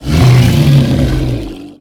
Sfx_creature_snowstalker_distantcall_03.ogg